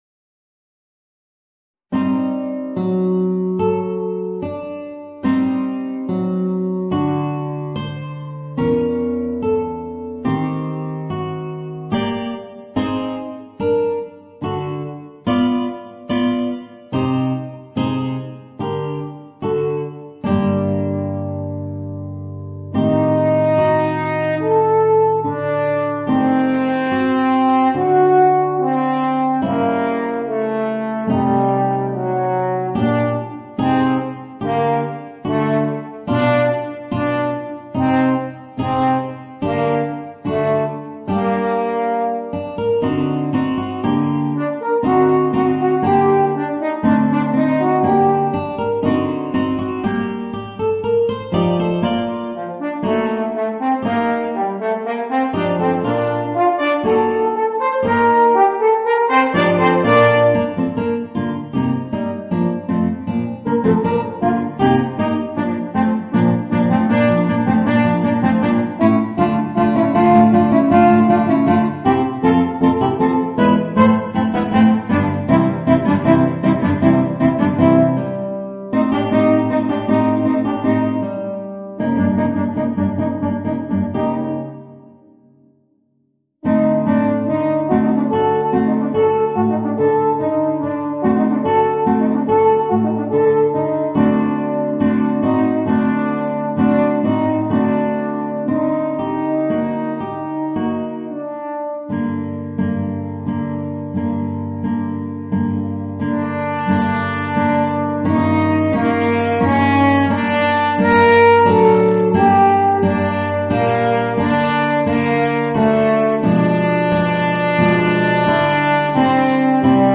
Voicing: Guitar and Horn